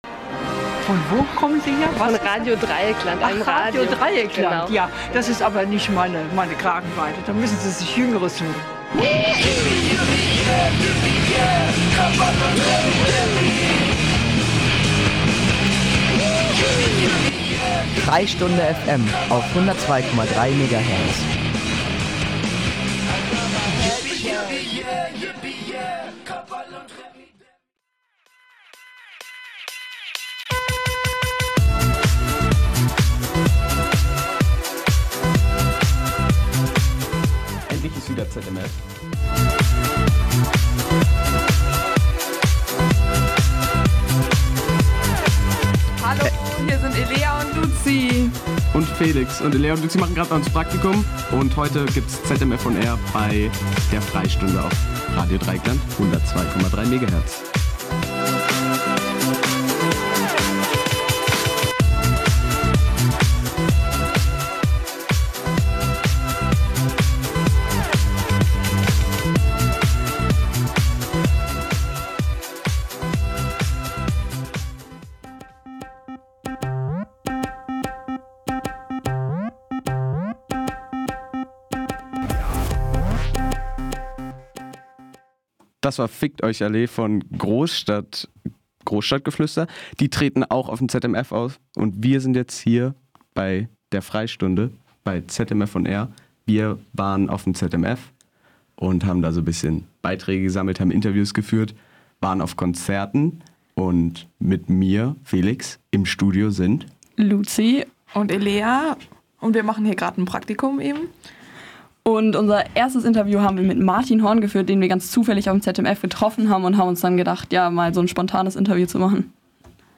Eine bunte Mischung aus Jugendlichen zwischen 14 und 23 Jahren Ein Mikrofon und die verschiedensten Musikgeschmäcker garantieren Abwechslung und jede Menge Spaß.